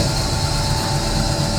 ELEVATOR_Movement_Loop_Noisy_Smoothed_loop_mono.wav